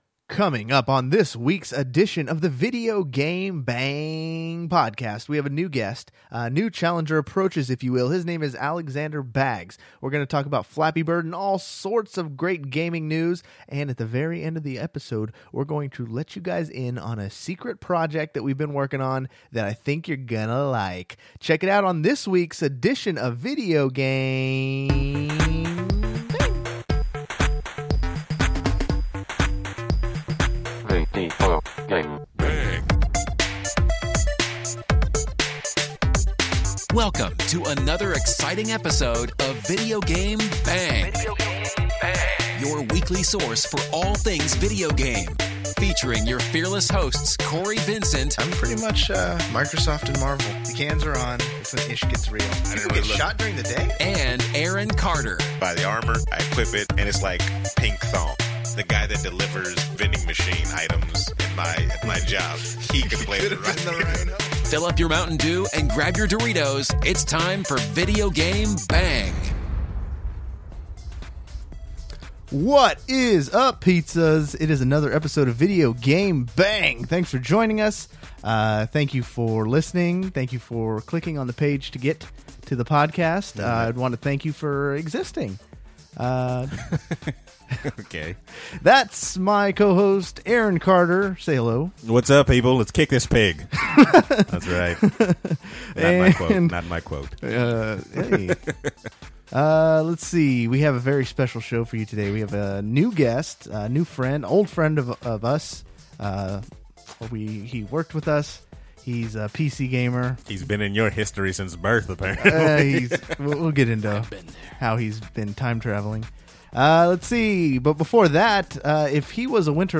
We have a first time in-studio guest.